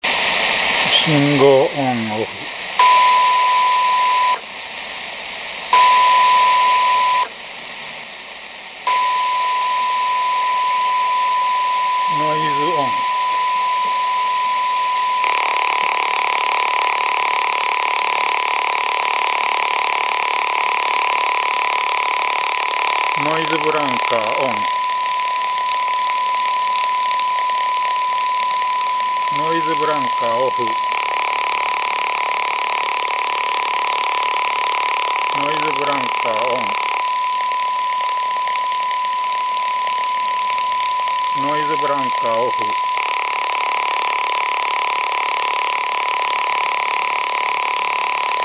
ノイズブランカの基礎実験 (ｸﾘｯｸすると実験中の受信音を聞ける･･･大音量に注意)
この実験で使ったﾉｲｽﾞ源は、ﾘﾚｰをﾌﾞｻﾞｰ接続して１秒間に約40回断続させた放電ﾉｲｽﾞです。
自動車のｴﾝｼﾞﾝ内で点火ﾌﾟﾗｸﾞにより発生するｲｸﾞﾆｯｼｮﾝに近い波形と思います。
ﾘﾚｰ接点のﾁｬﾀﾘﾝｸﾞによって不規則なところもありますが、手頃なﾉｲｽﾞ発生源です。